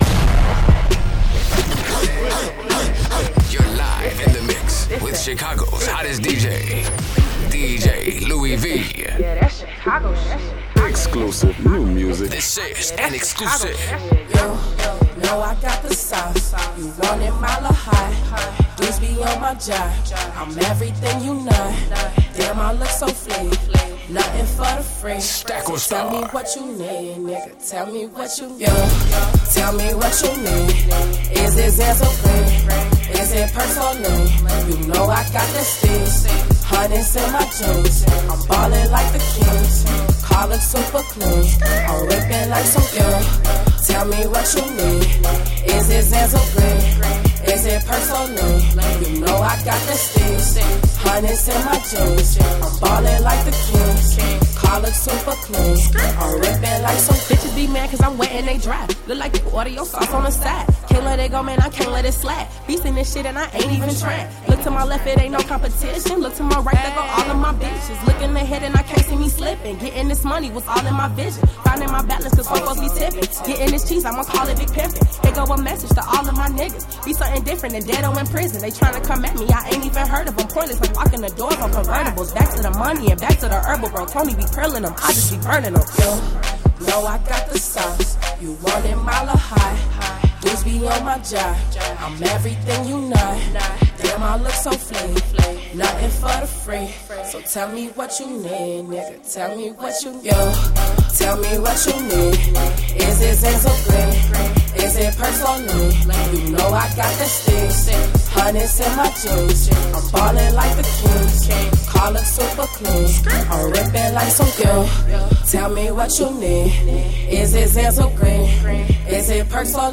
Hiphop
female raptress
a nice party vibe song